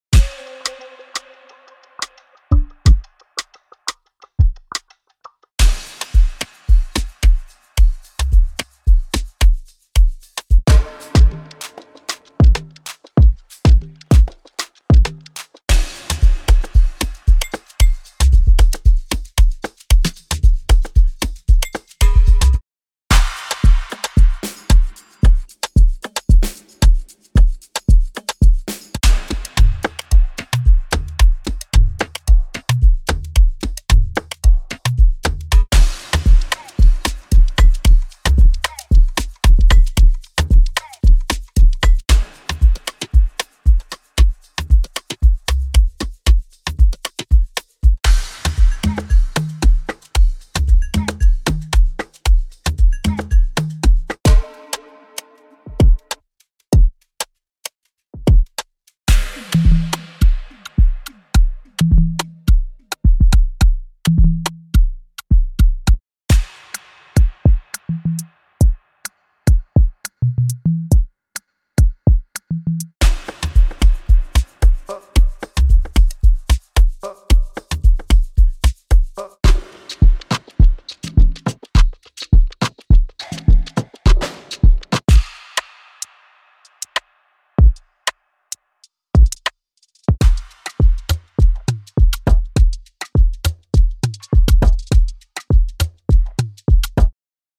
16 pristine afrobeats/amapiano drumloops, just drag and drop.
– 16 drumloops